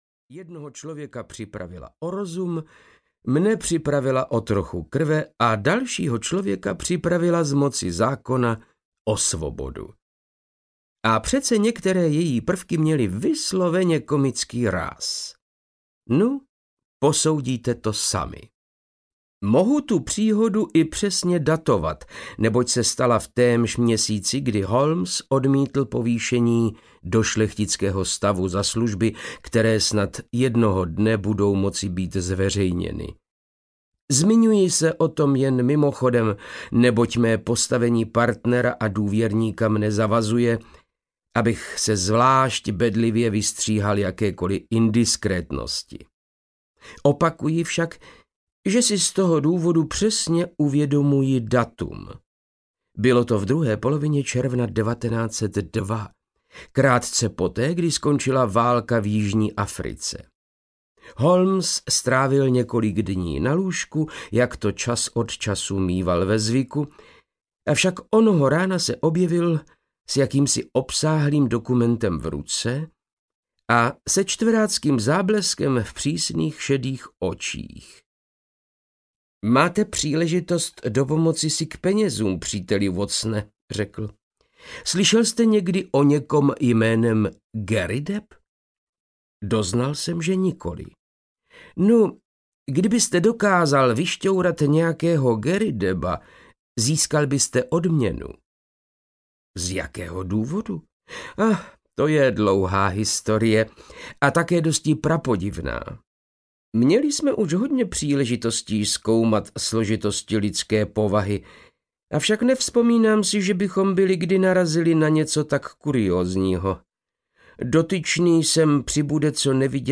Audio knihaTři Garridebové
Ukázka z knihy
• InterpretVáclav Knop